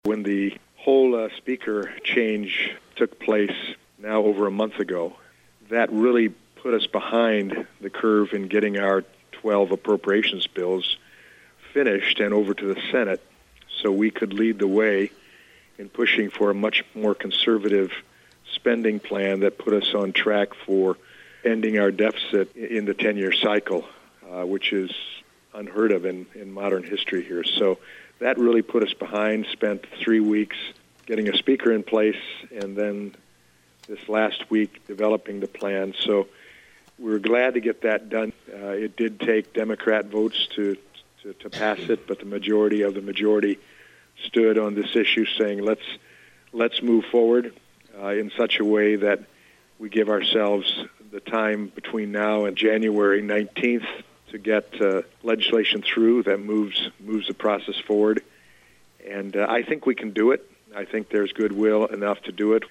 Washington, D.C. – The US House of Representatives voted to prevent a government shutdown earlier this week, and our local Congressman gave his reaction on a recent 7:40 program.